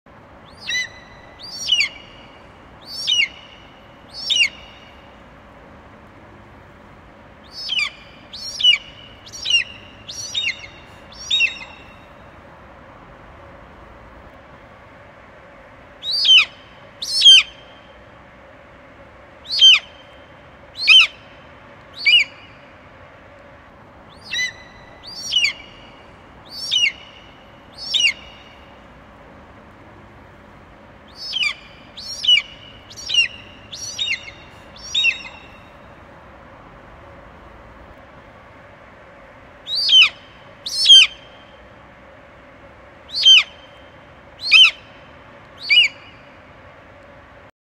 دانلود صدای عقاب 2 از ساعد نیوز با لینک مستقیم و کیفیت بالا
جلوه های صوتی
برچسب: دانلود آهنگ های افکت صوتی انسان و موجودات زنده دانلود آلبوم صدای عقاب در اوج آسمان از افکت صوتی انسان و موجودات زنده